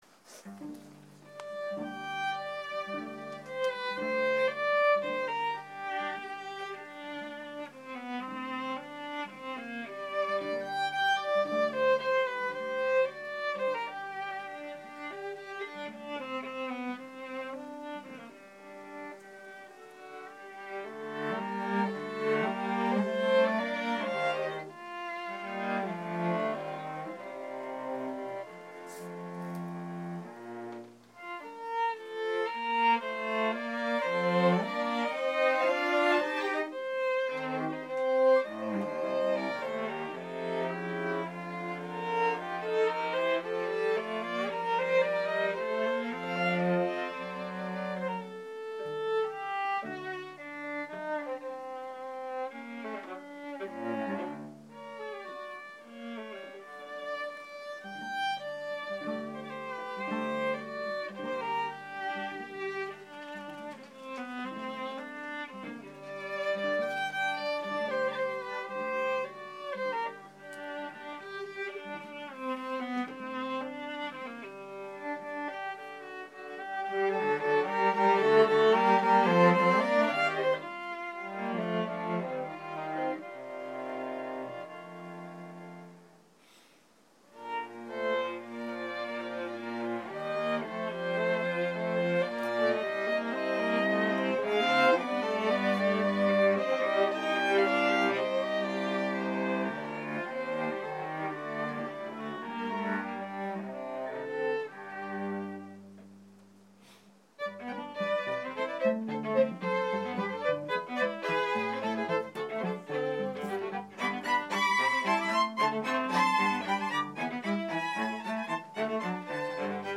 Chamber, Choral & Orchestral Music